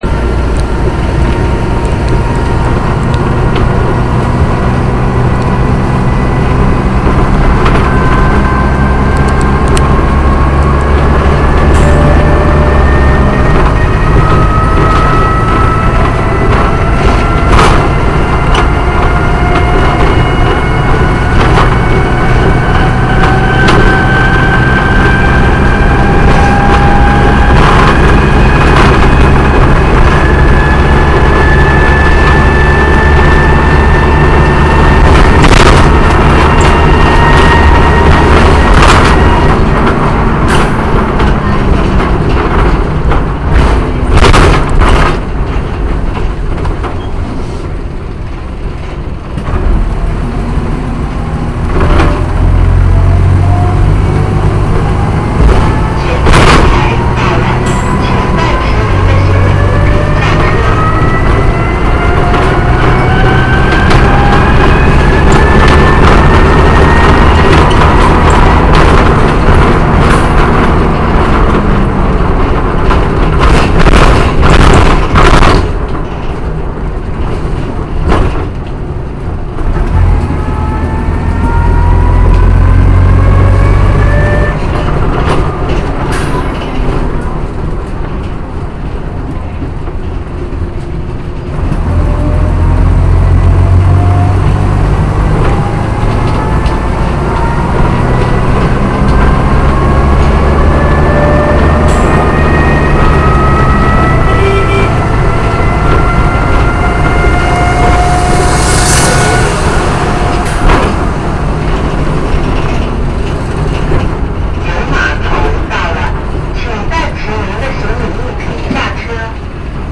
所有巨鷹珍寶仍然採用原裝吉拿 6LXB 引擎及利蘭 4 波波箱，但轉波方式已改為「電波」，即加上按鈕，按下按鈕轉波。
• 行走路線︰深圳 226 路（赤灣 → 西麗動物園）
本錄音攝於赤灣小區內的道路，值得留意是於 1 分 49 秒的風缸放風聲，在巨鷹珍寶來說是非常少有的。